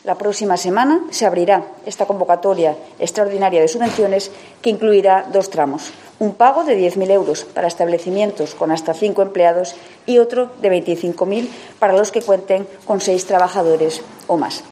La portavoz del gobierno explica las ayudas al ocio nocturno